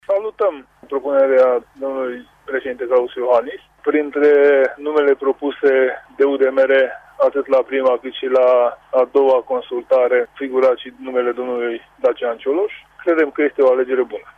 UDMR spune că va avea o serie de propuneri de tehnocraţi care să facă parte din noul Guvern. Liderul senatorilor UDMR, Tanczos Barna, a precizat că Uniunea este de acord cu desemnarea lui Dacian Cioloş: